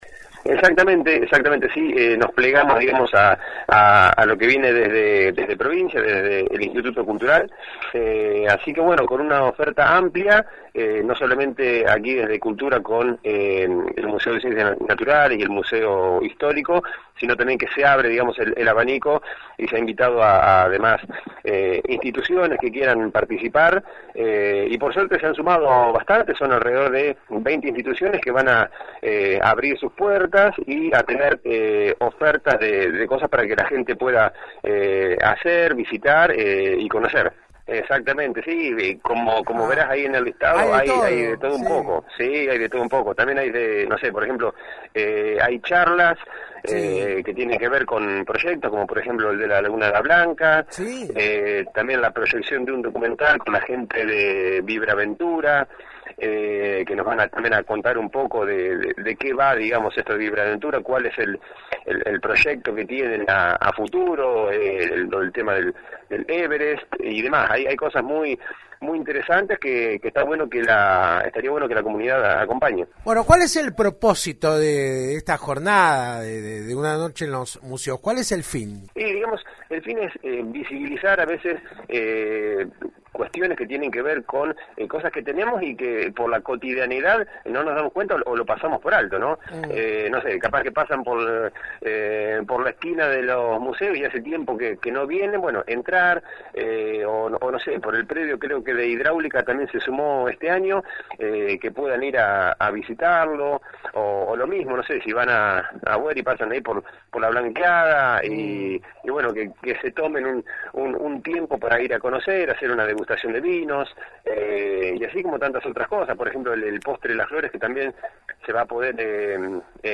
Por tal motivo, hablamos con el Director de Cultura municipal, quien se refirió a los preparativos para el fin de semana.